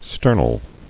[ster·nal]